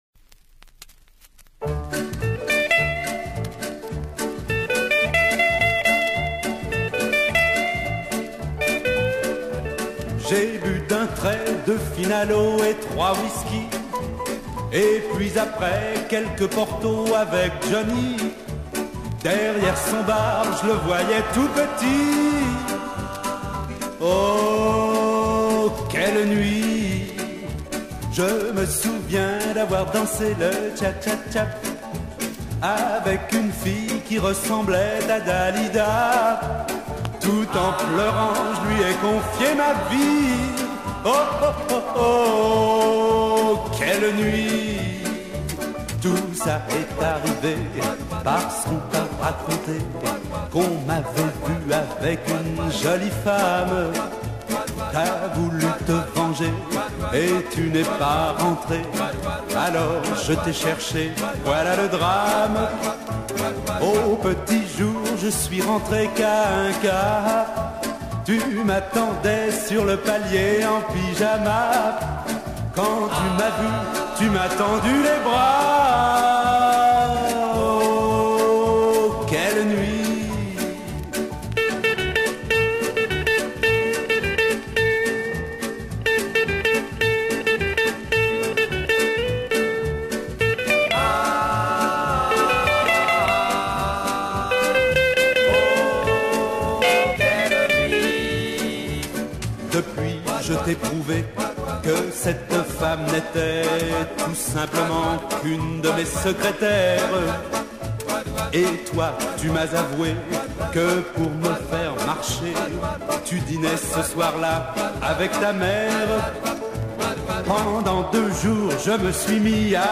qui enregistre un disque dans la carlingue, pendant le vol